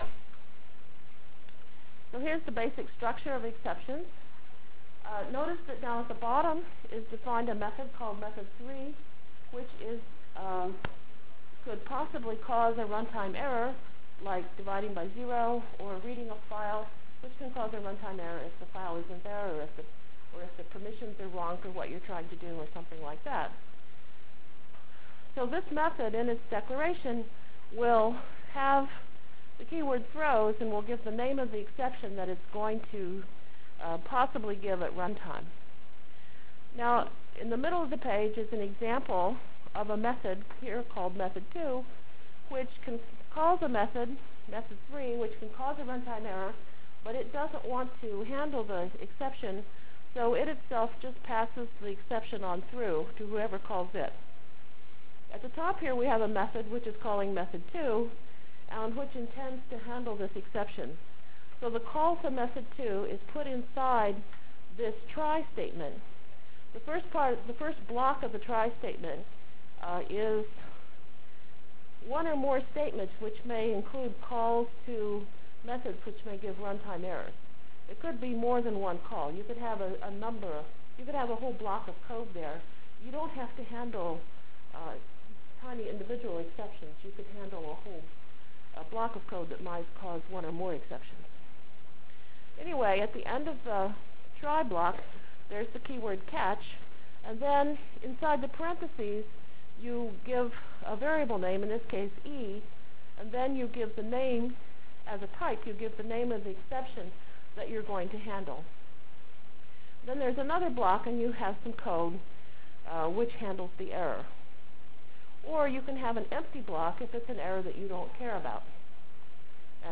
From Jan 29 Delivered Lecture for Course CPS616 -- Java Lecture 3 -- Exceptions Through Events CPS616 spring 1997 -- Jan 29 1997.